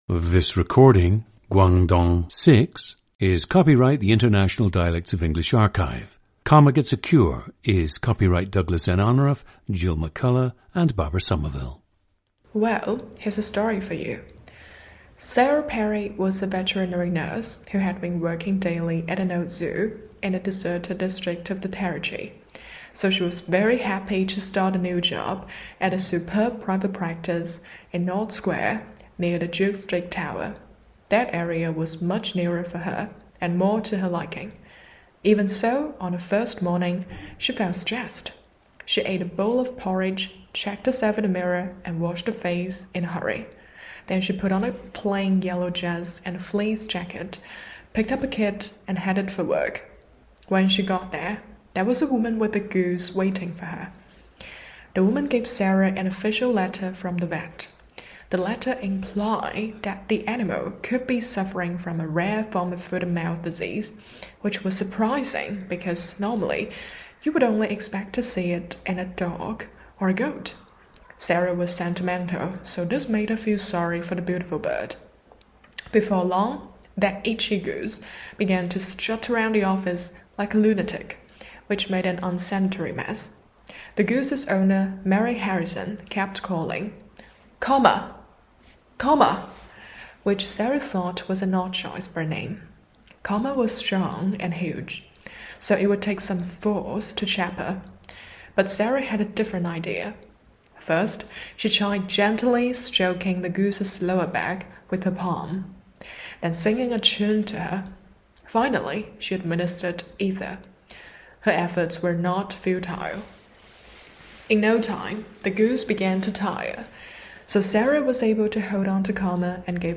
GENDER: female
The subject’s accent is closer to British English, specifically Received Pronunciation, than possibly any other Chinese subject on IDEA. The chief sounds that suggest she is Chinese are her stereotypical use of the letter L, particularly at the ends of words, and her stress pattern.